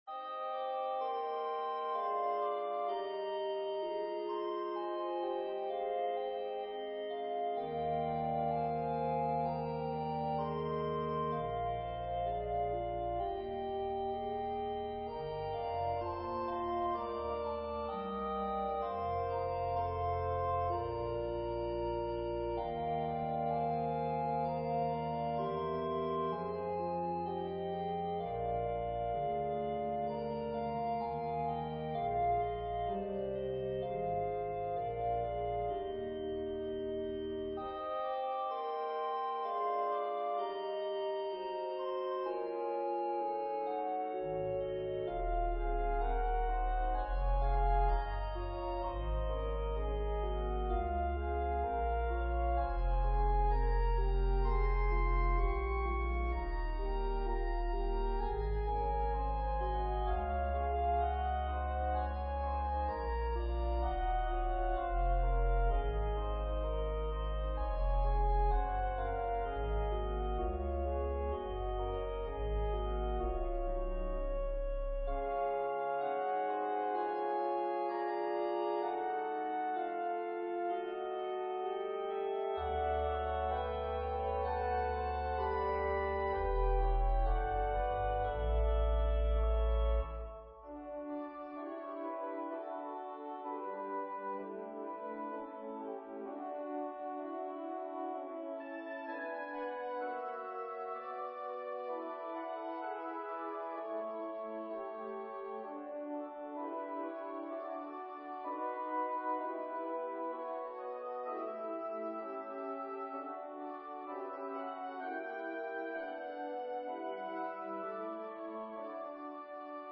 arranged for organ solo
Voicing/Instrumentation: Organ/Organ Accompaniment